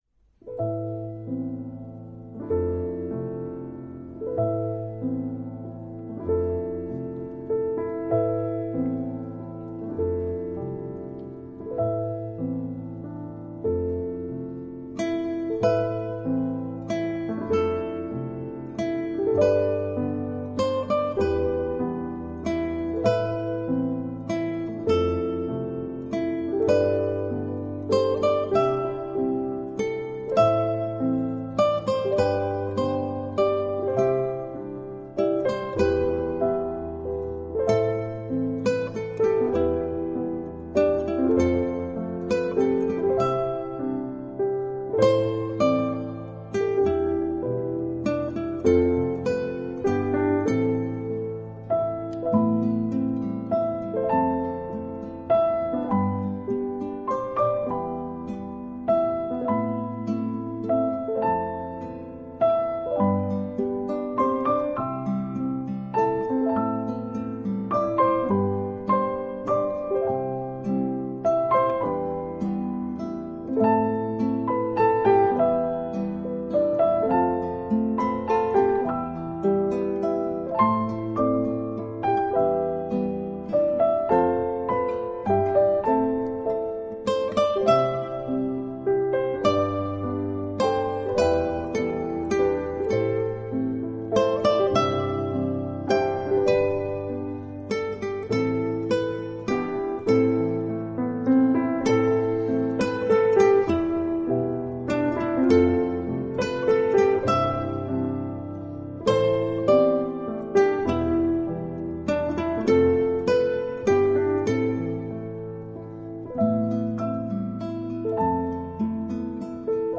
本系列所有音樂，都在日本「八之岳山麓」製作完成。